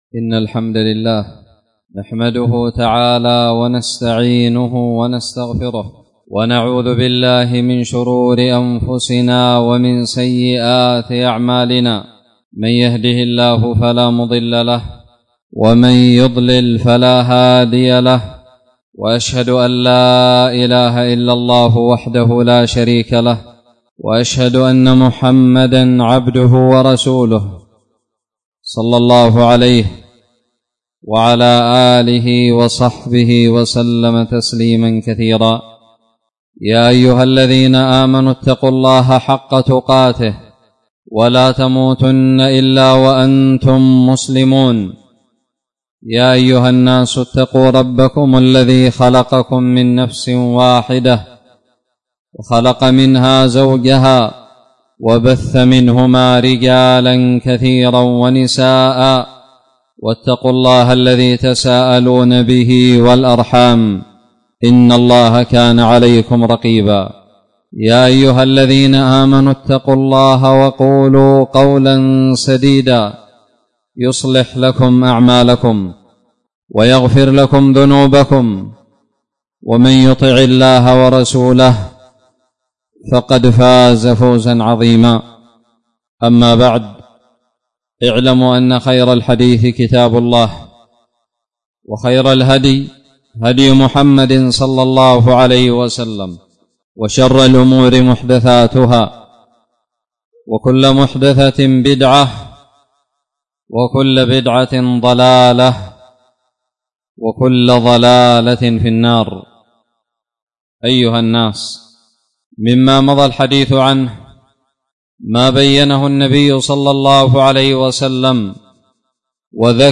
خطب الجمعة
ألقيت بدار الحديث السلفية للعلوم الشرعية بالضالع في 10 رجب 1441هــ